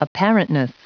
Prononciation du mot apparentness en anglais (fichier audio)
Prononciation du mot : apparentness